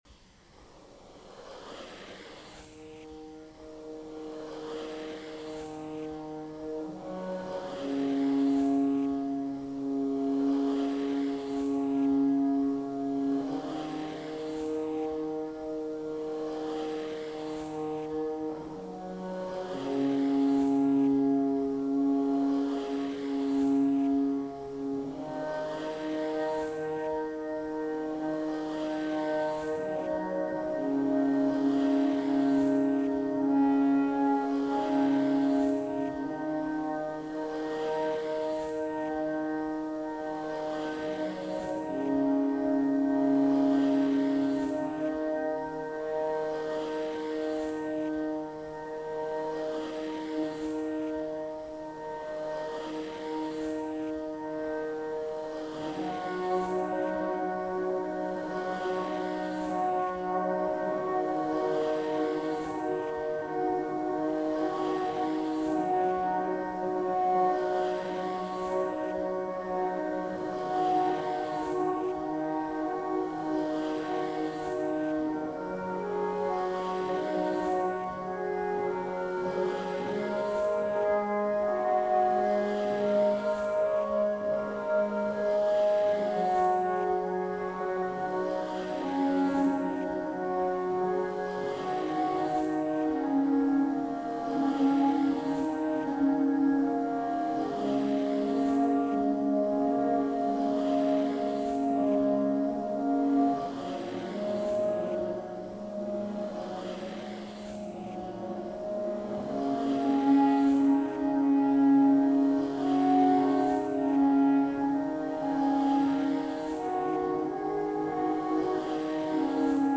Genre: Experimental Music.